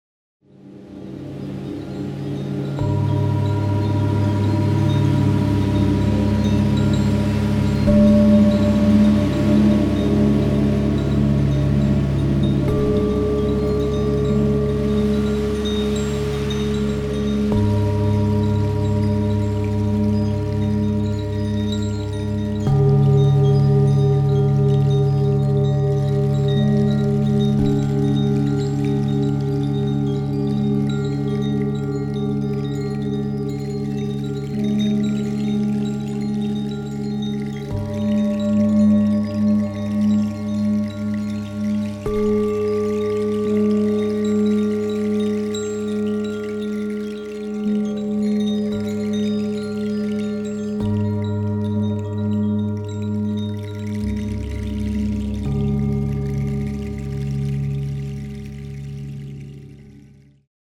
Diese CD besitzt sehr tiefe und sehr hohe Töne.